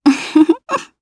Gremory-Vox_Happy2_jp.wav